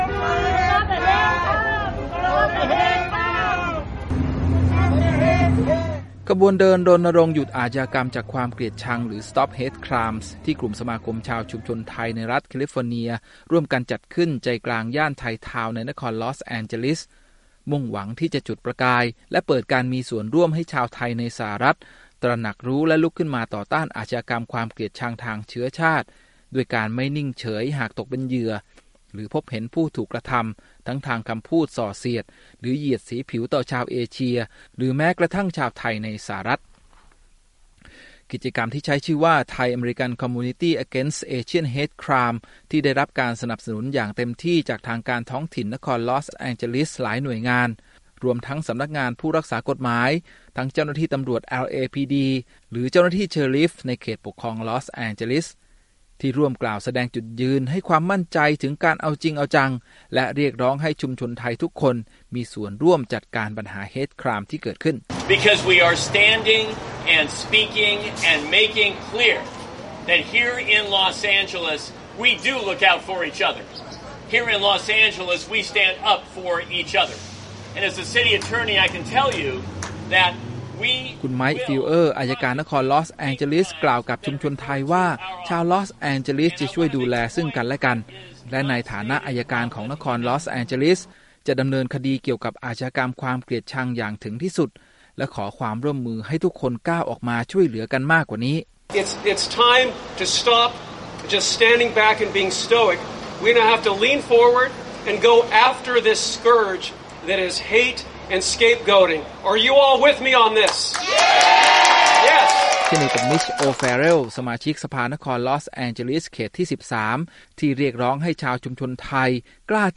Thai Town Community Rally Against Hate Crime